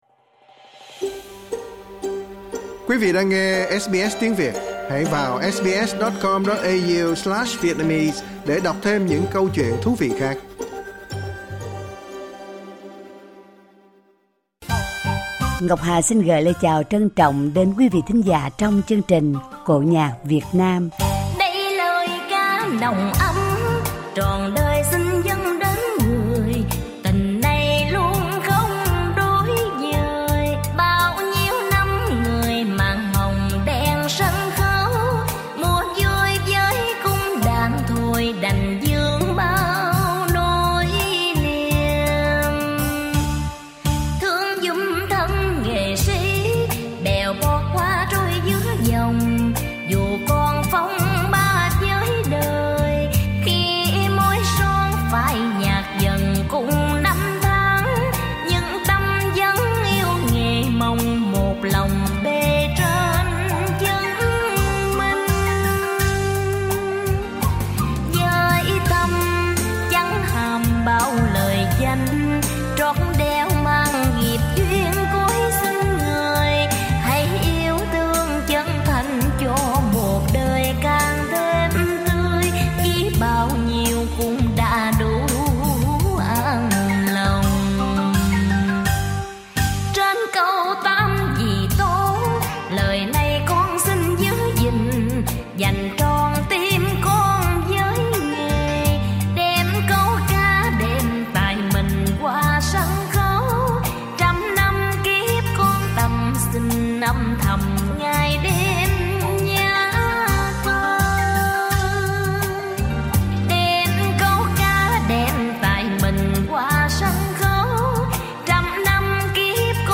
bài tân cổ